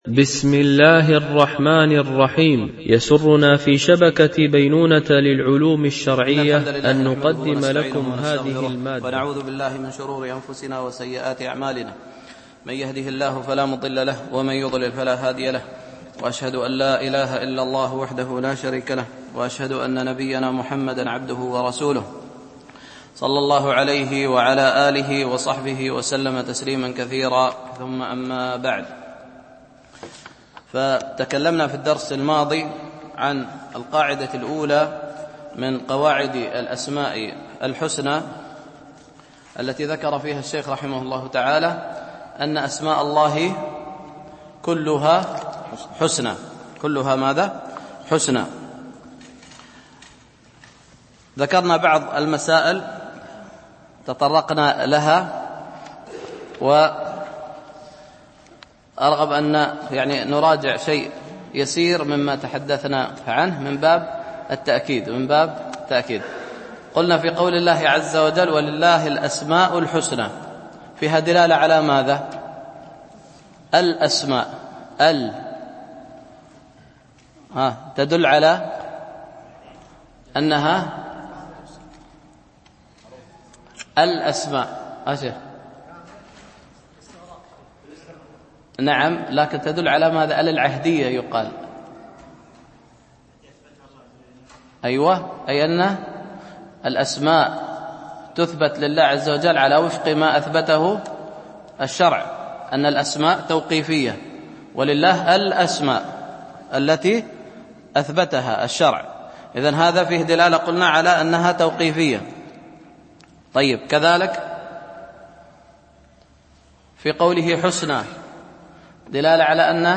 دروس مسجد عائشة (برعاية مركز رياض الصالحين ـ بدبي)